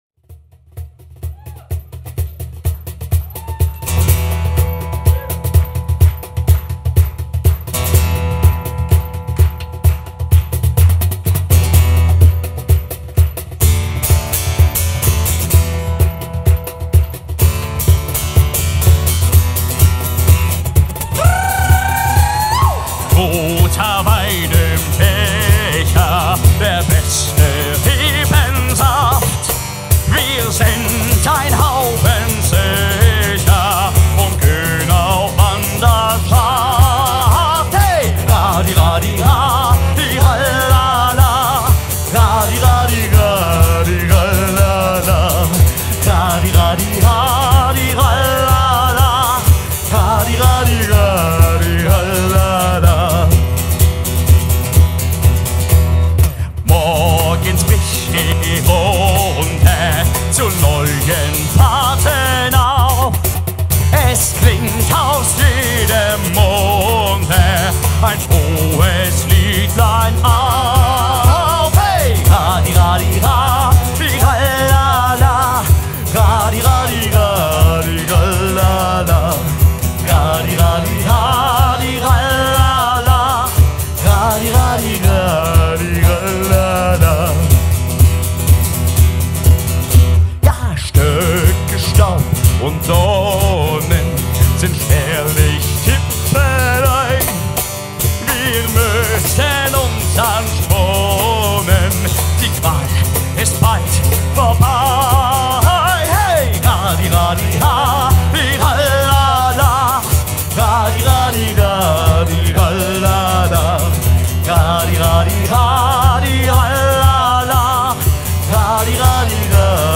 Live
Live am 15.12.2018 in Frankfurt a.M.